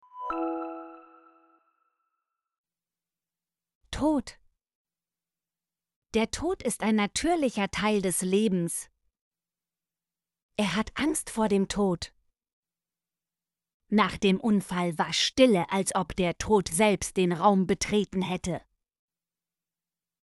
tod - Example Sentences & Pronunciation, German Frequency List